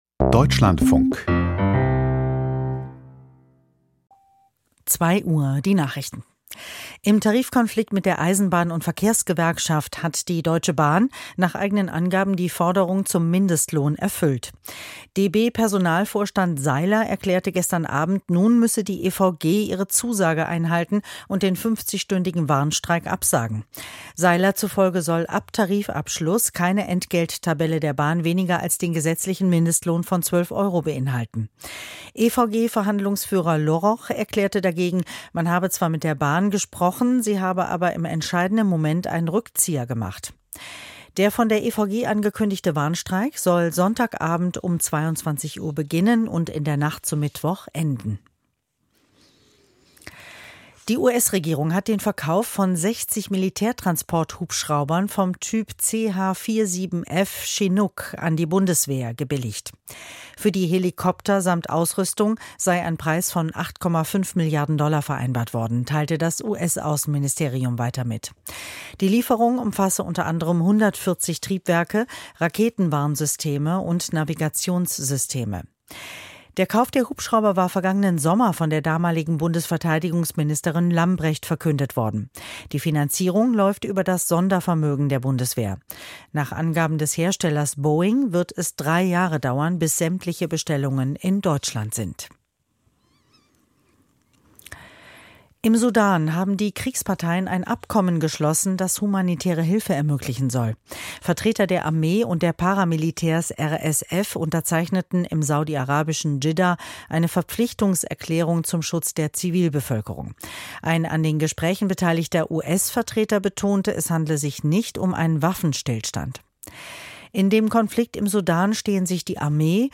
Nachrichten vom 12.05.2023, 02:00 Uhr